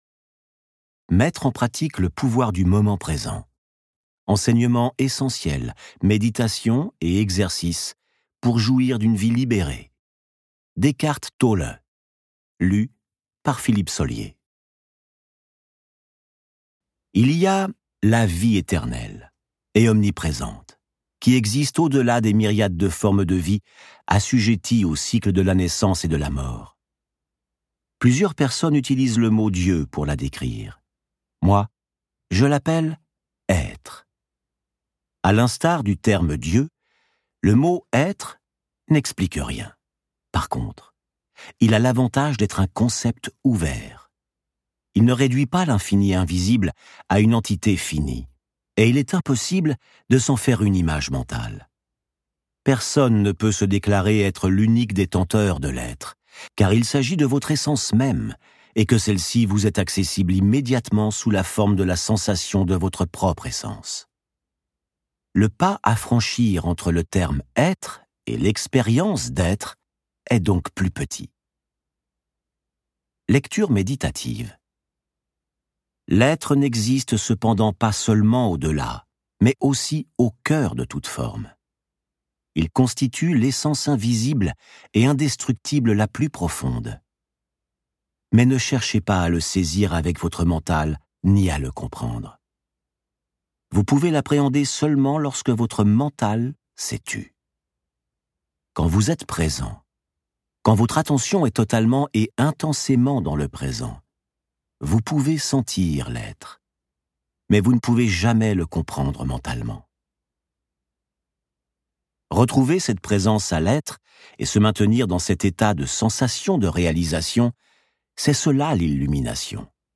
Eckhart Tolle propose des méditations et exercices pour arriver à un état de conscience modifié, en dépassant les schémas de la souffrance et de la négativité. Avec ce livre audio, donnez une place centrale à la réflexion personnelle et profonde.